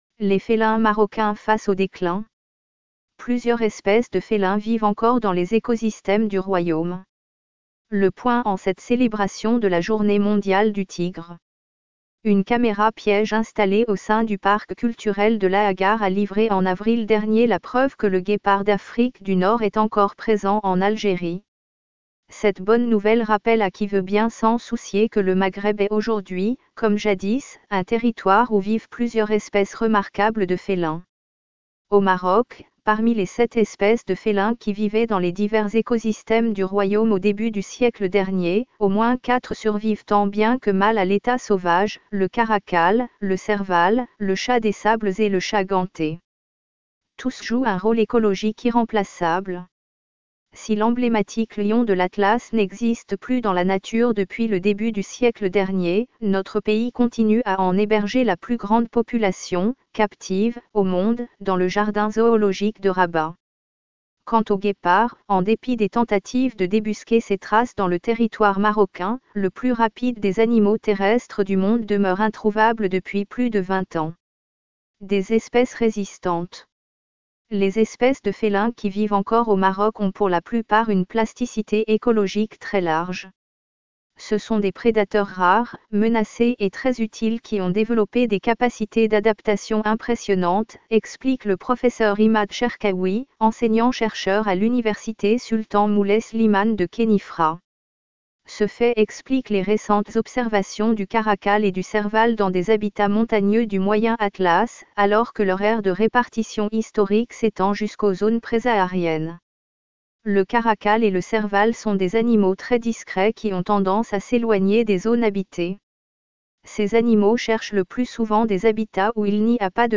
Article à écouter en podcast